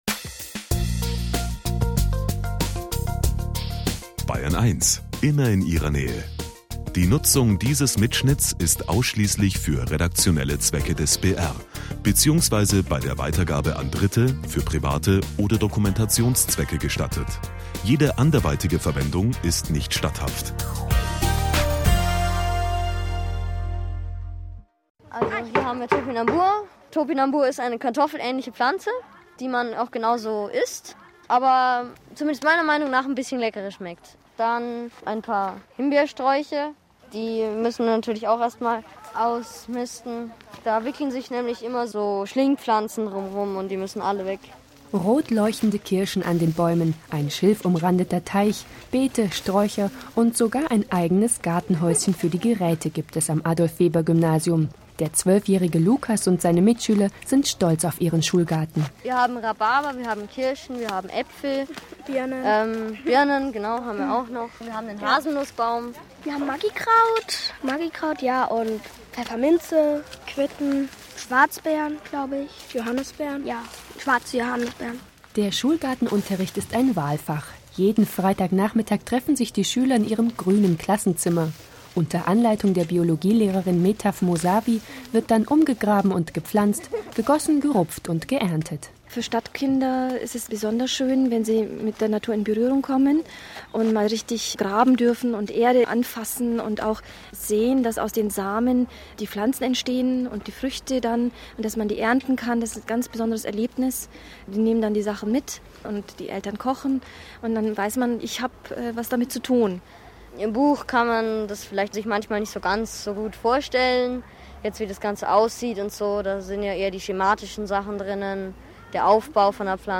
Bayerischer Rundfunk kommt zum Interview
"Tag der offenen Schulgärten" teilgenommen.